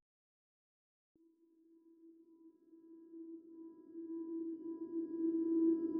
Hextris Intro Buildup Sweep.wav